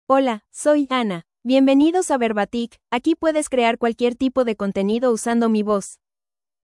FemaleSpanish (United States)
AnnaFemale Spanish AI voice
Voice sample
Anna delivers clear pronunciation with authentic United States Spanish intonation, making your content sound professionally produced.